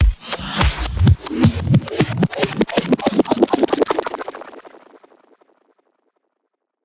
VEC3 Reverse FX
VEC3 FX Reverse 58.wav